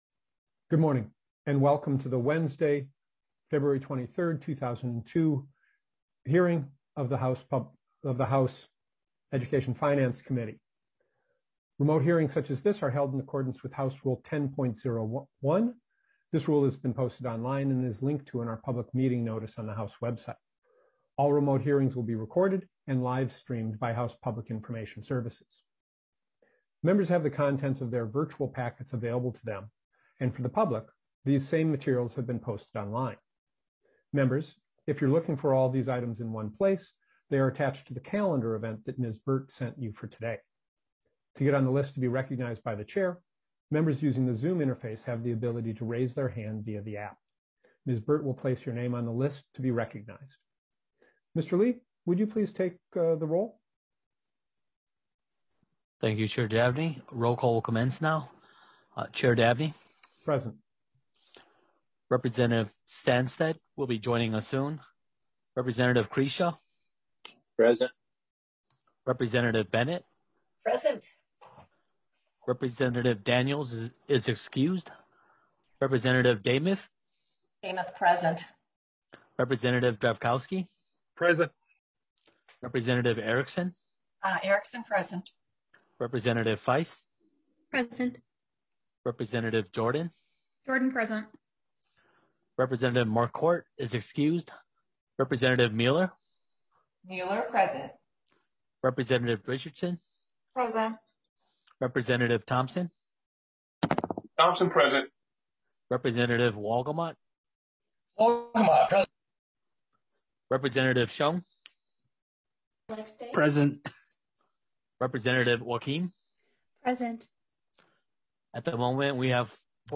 Education Finance FORTY SEVENTH MEETING - Minnesota House of Representatives